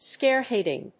アクセント・音節scáre・hèading